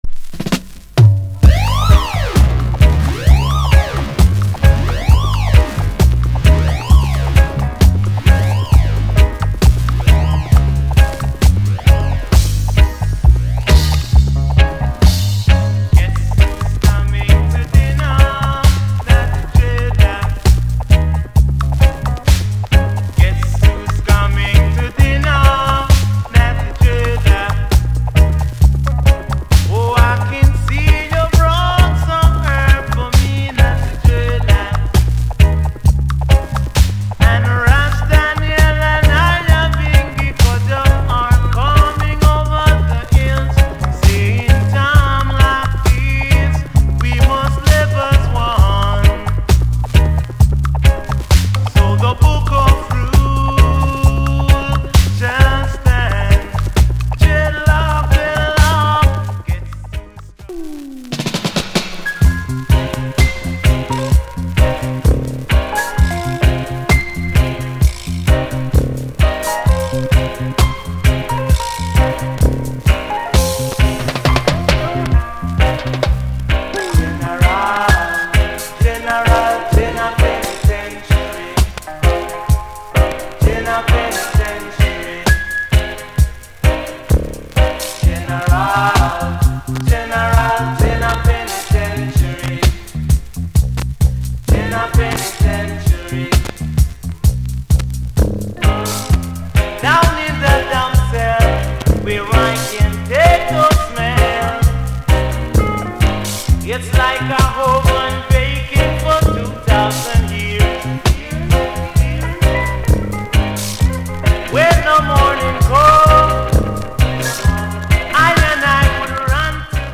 Genre Reggae80sEarly / Group Vocal
* Deep Vocal